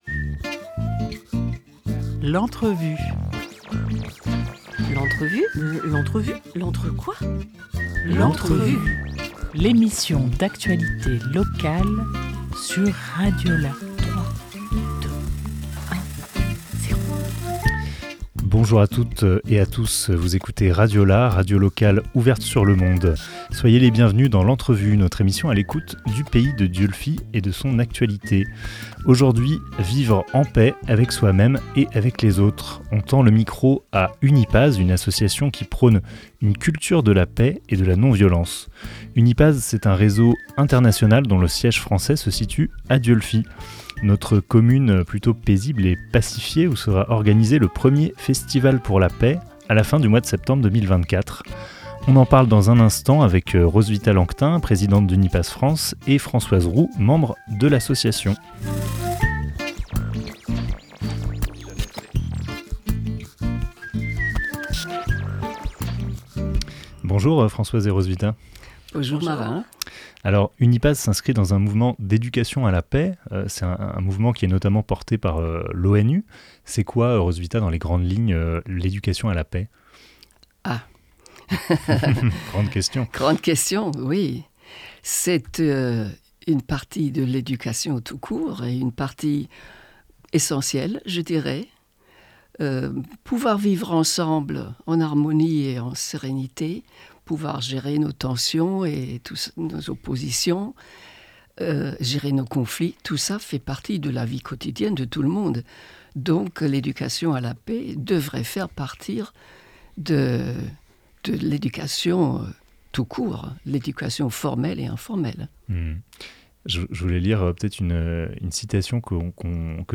23 juillet 2024 11:08 | Interview